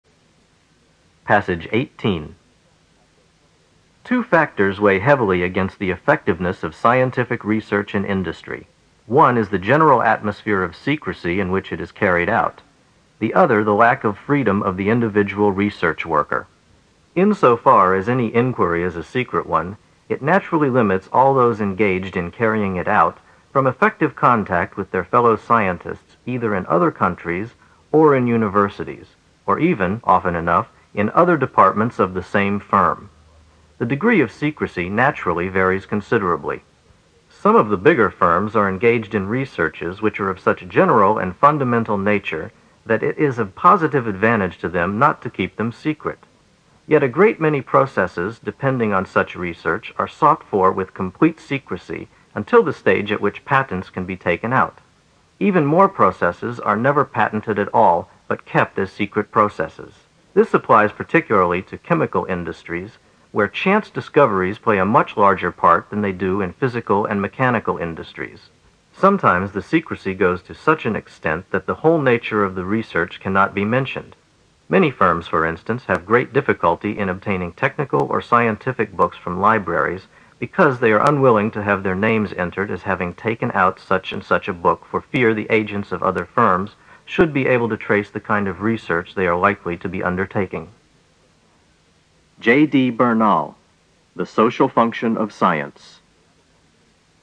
新概念英语85年上外美音版第四册 第18课 听力文件下载—在线英语听力室